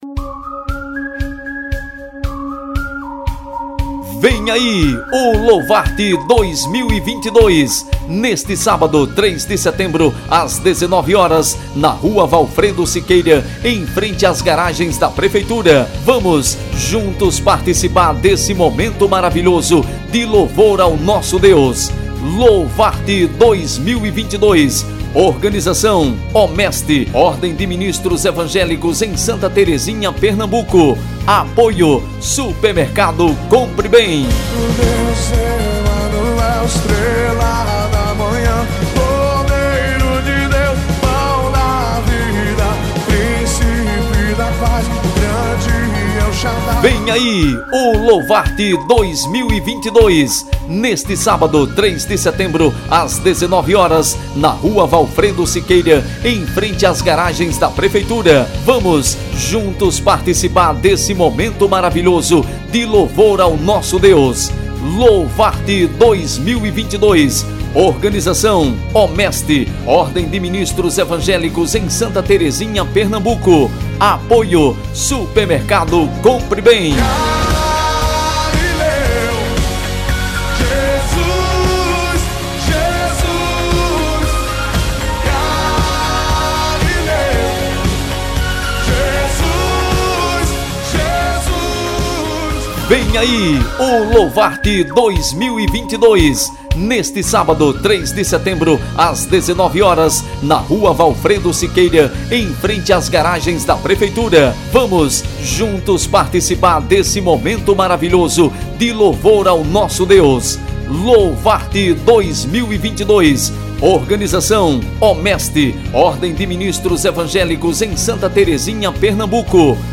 CONFIRA A CHAMADA PARA O EVENTO.